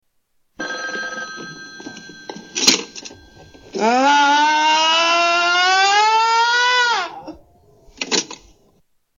Scream